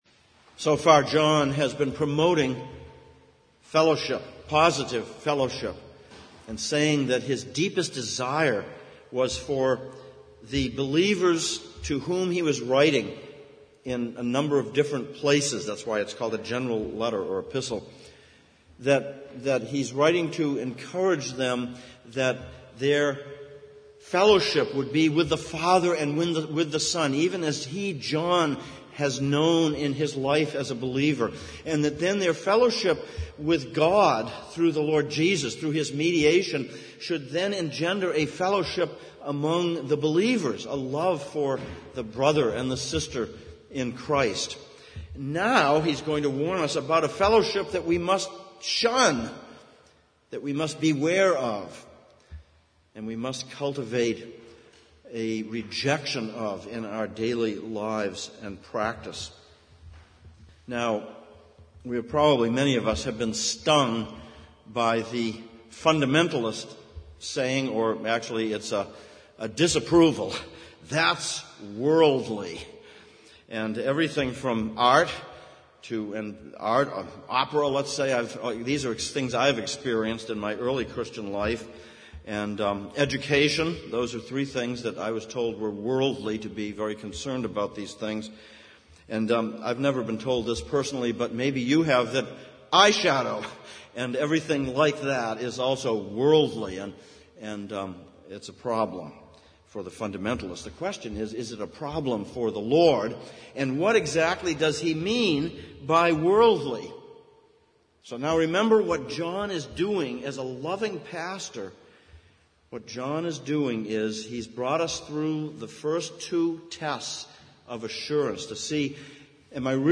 Passage: 1 John 2:15-29, Ezekiel 33:21-33 Service Type: Sunday Morning Sermon